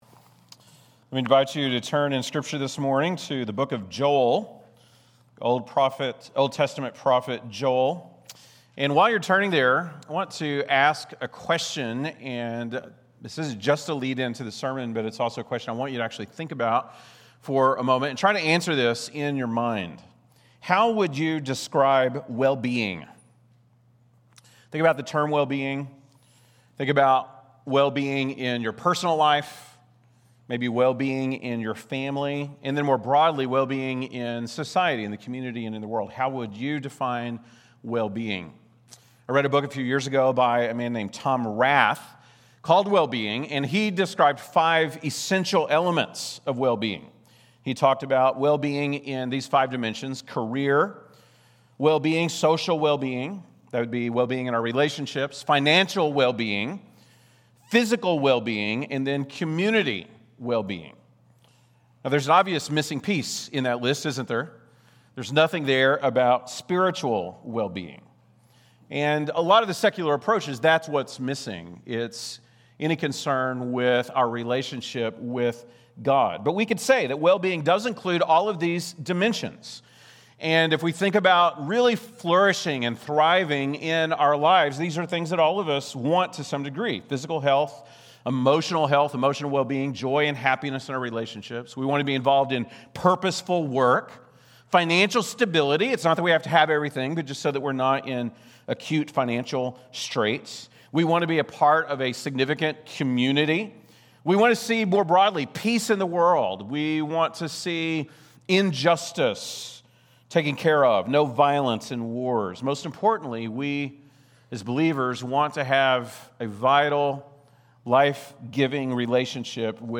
August 10, 2025 (Sunday Morning)